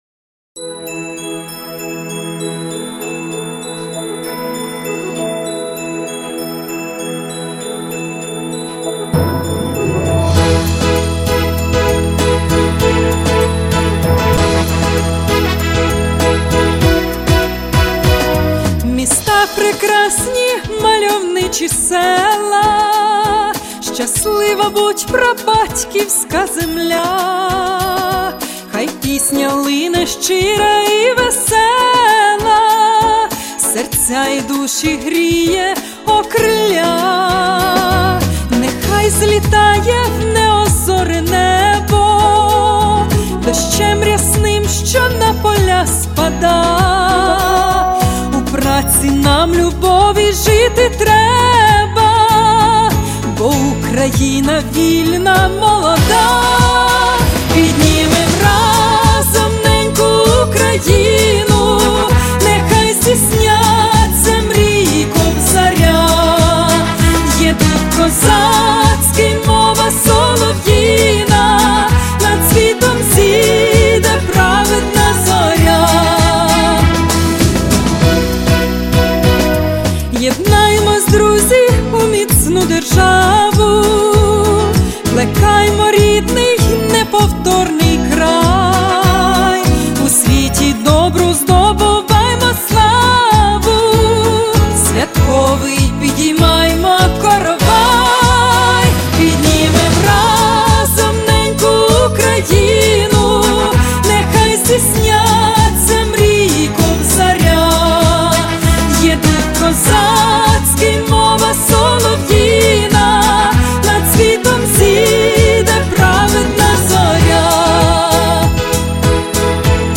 Чудова, справжня українська пісня!